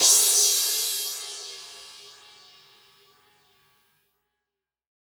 DnBCymbalA-01.wav